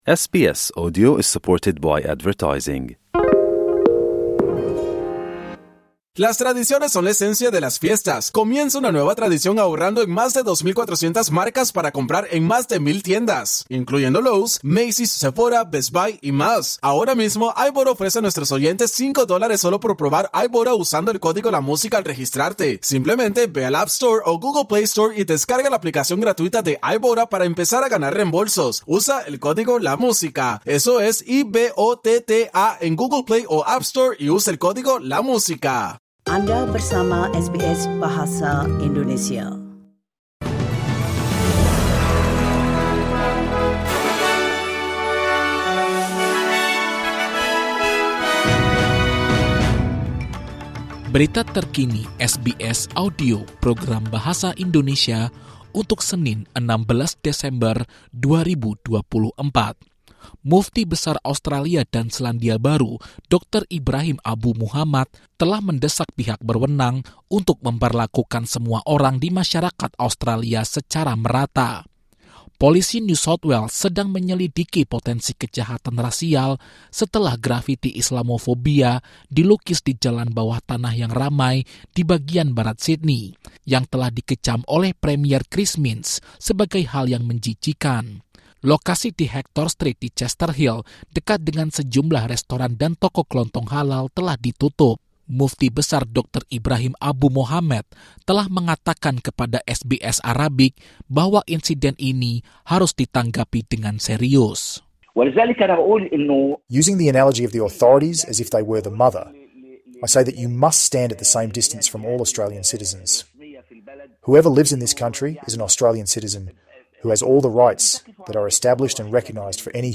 Latest News SBS Audio Indonesian Program - 16 December 2024 - Berita Terkini SBS Audio Program Bahasa Indonesia - 16 Desember 2024